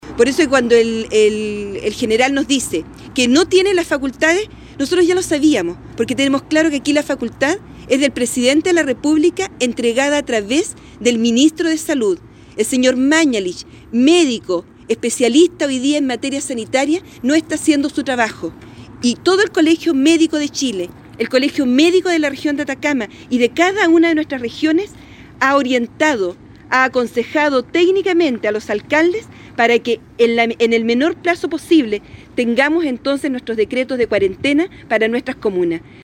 La tarde de este viernes, los alcaldes de la región se reunieron con el Jefe de Zona de Atacama, General Enrique Heyermann, tras lo cual, la alcaldesa de Caldera, Brunilda Gonzalez como vocera entregó una declaración señalando que el miércoles pasado el Intendente Urquieta les habría dicho que solo el Jefe de Zona podía decretar cuarentena regional.